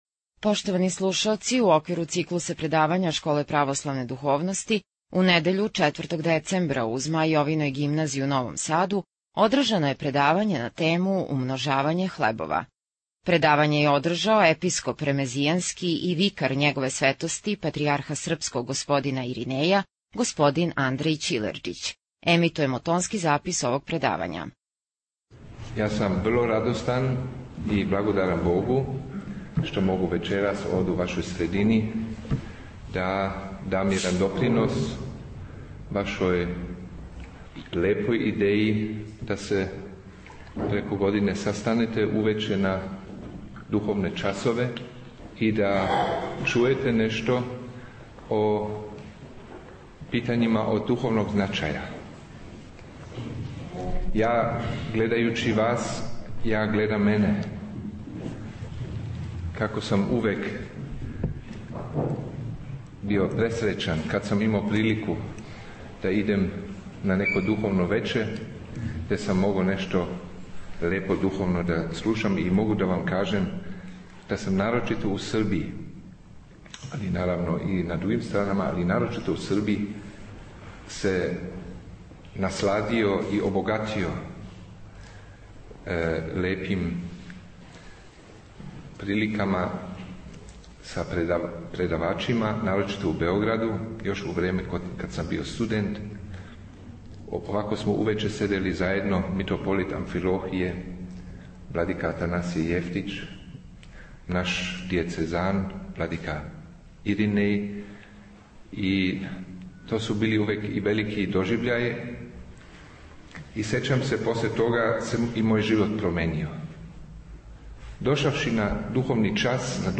Пред многобројним слушаоцима сабраним у свечаној дворани Змај Јовине Гимназије у Новом Саду, у недељу 4. децембра 2011. године, Његово Преосвештенство Епископ ремезијански г. Андреј одржао је предавање на тему Умножење хлебова.
• Питања и одговори: